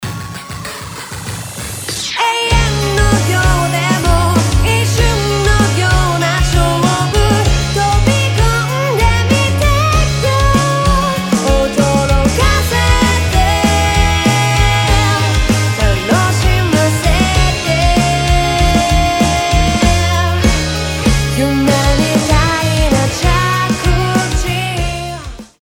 「リアンプ後の2MIX」
ボーカルの抜けが良くなっており、サウンド全体の輪郭がハッキリとしています。
ベース、ドラムに対してもサチュレーション感（適度な歪み感）が加わり、存在感のあるサウンドになっているのが印象的です。
抜けが良くなったにも関わらず、しっかりと楽曲になじんでいるというのがポイントです。